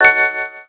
shield_picked.wav